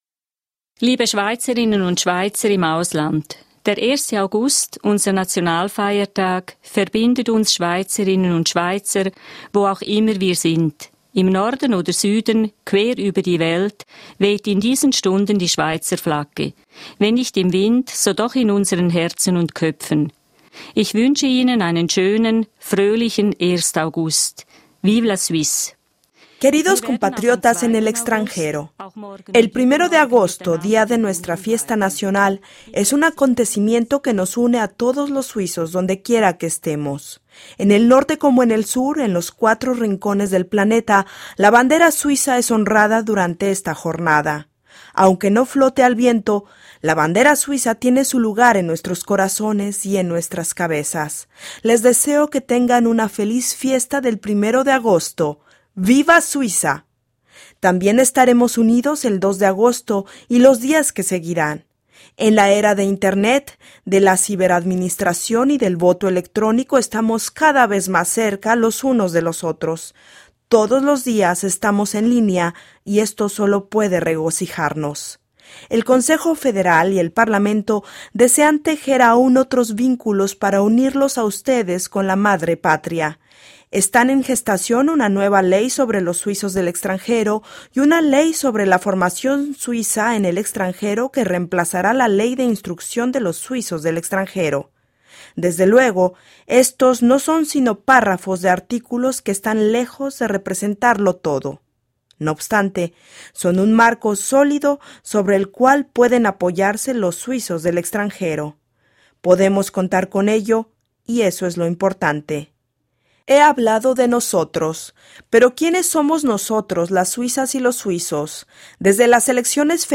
Discurso de la presidenta de la Confederación Eveline Widmer-Schlumpf a los suizos del exterior.